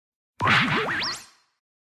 Anime Question Mark Sound Effect